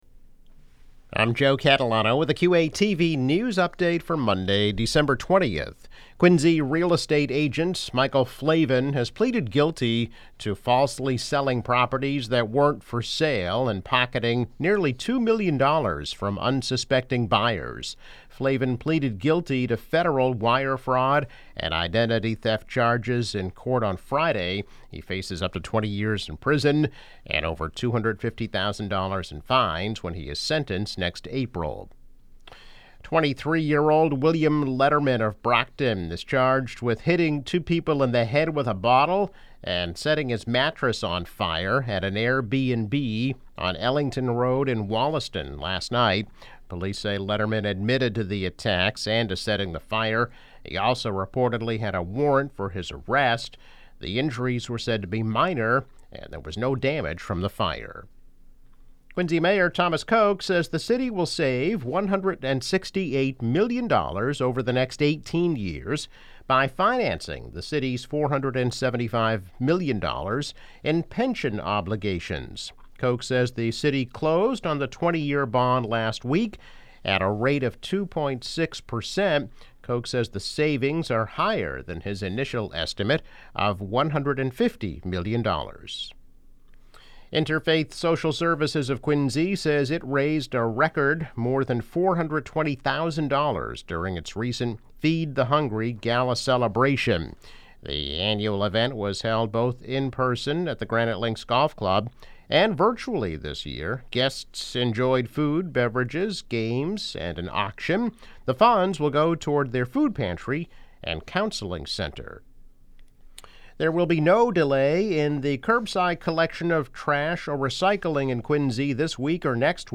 News Update - December 20, 2021